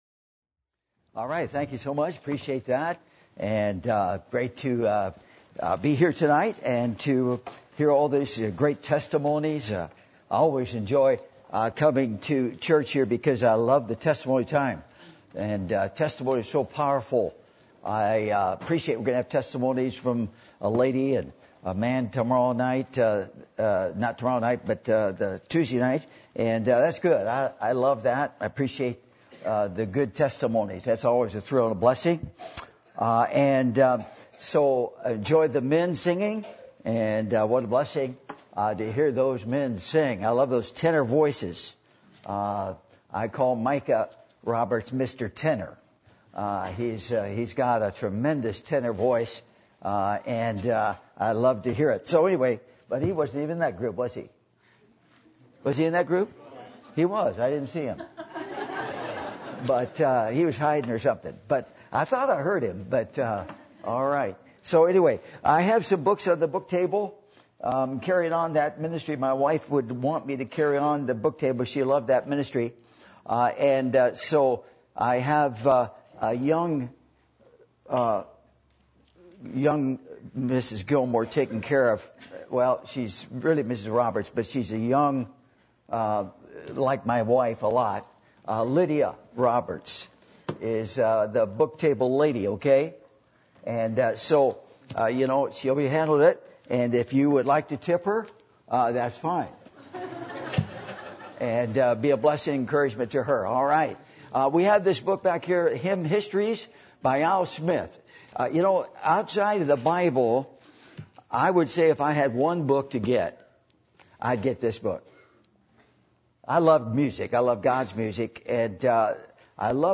Passage: Matthew 6:19-21 Service Type: Sunday Evening